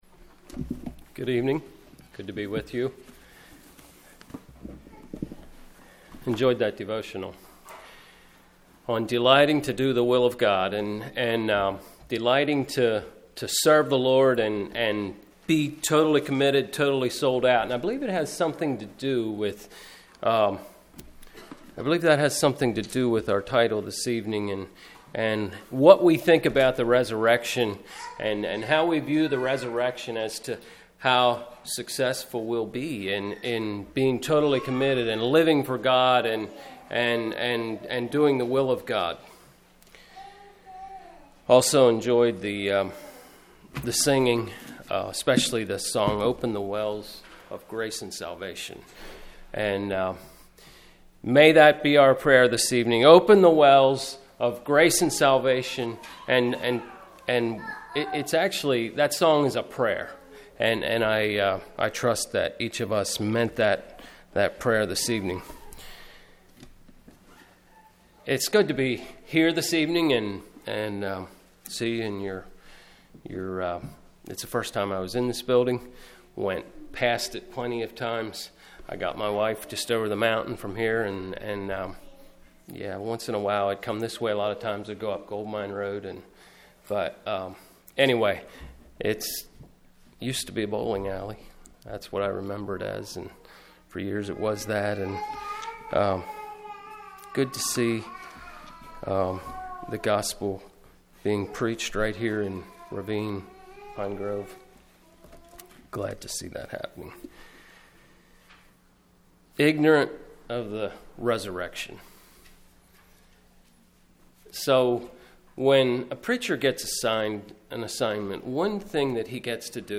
A teaching of the resurrection/ 2nd coming of the Lord Categories